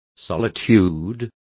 Complete with pronunciation of the translation of solitudes.